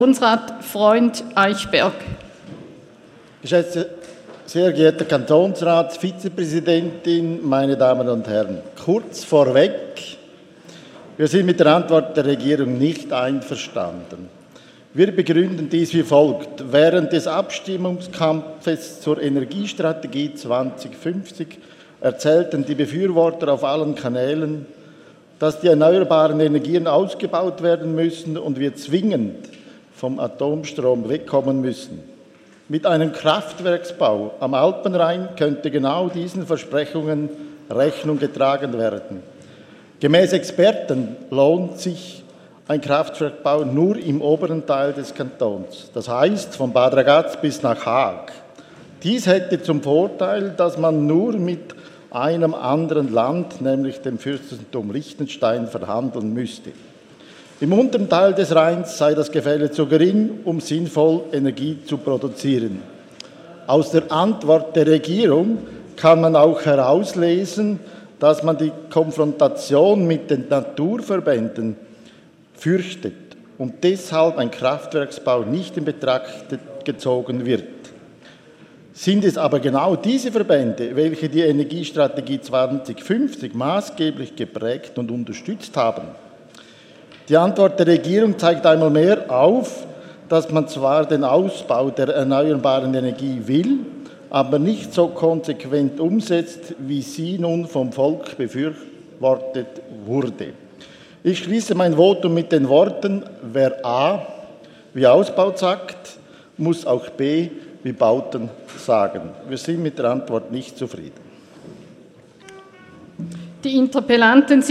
27.11.2017Wortmeldung
Session des Kantonsrates vom 27. und 28. November 2017